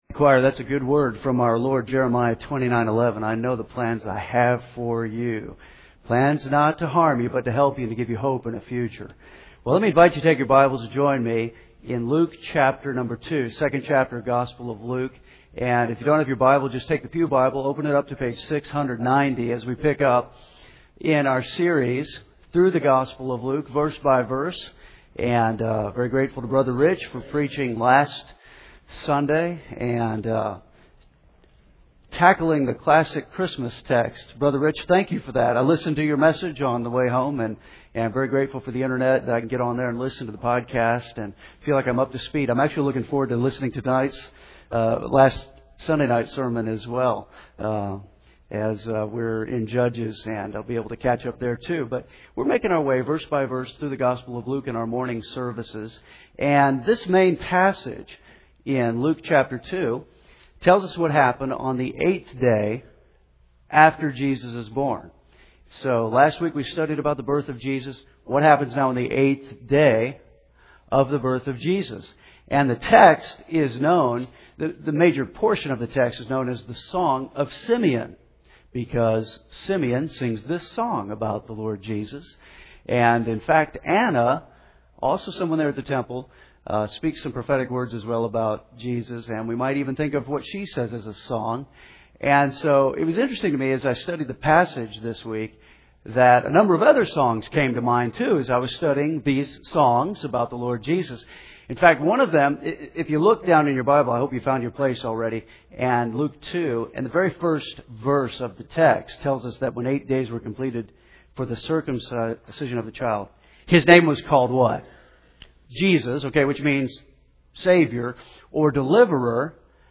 Henderson’s First Baptist Church, Henderson KY